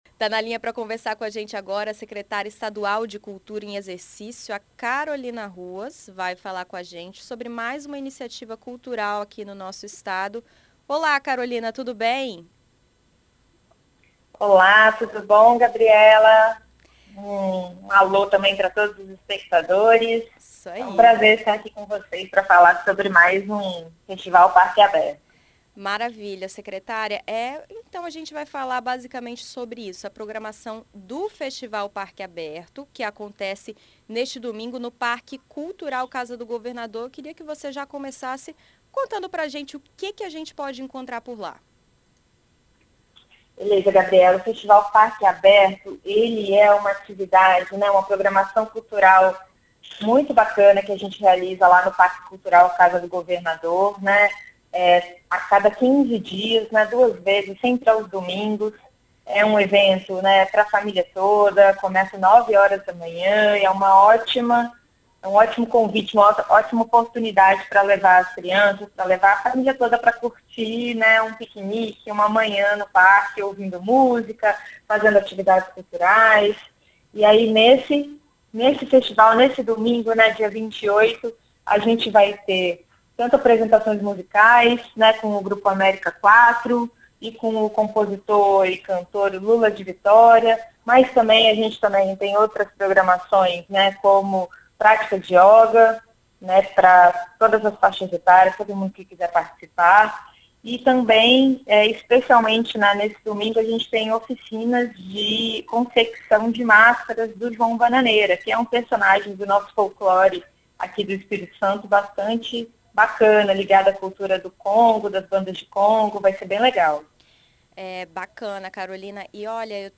Em entrevista a BandNews FM Espírito Santo nesta sexta-feira (26), Subsecretária de Estado de Políticas Culturais, Carolina Ruas, dá detalhes sobre a programação.